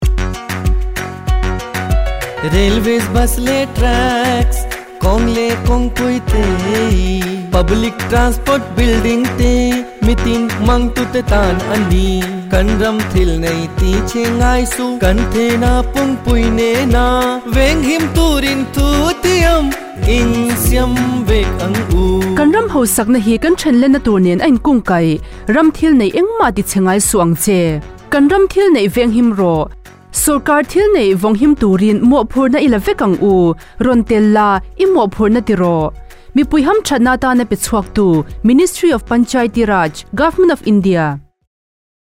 165 Fundamental Duty 9th Fundamental Duty Safeguard public property Radio Jingle Mizo